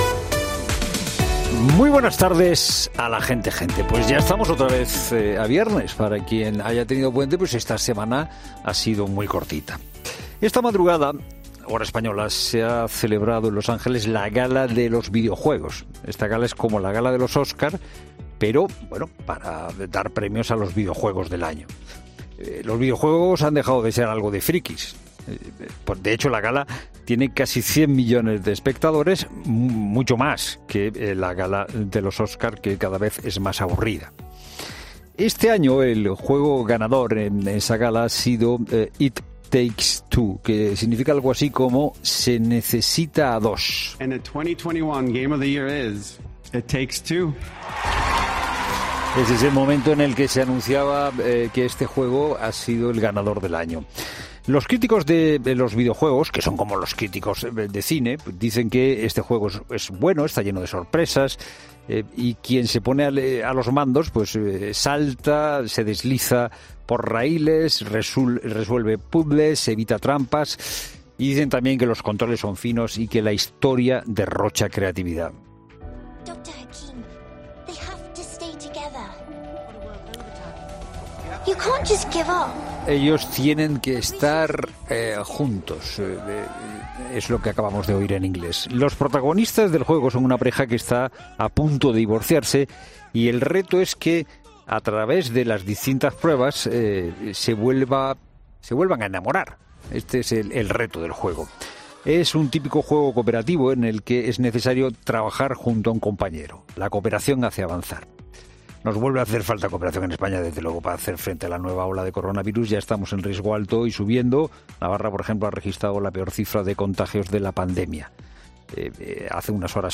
Monólogo de Fernando de Haro
El copresentador de 'La Tarde', Fernando de Haro, reflexiona sobre las principales noticias de actualidad en su monólogo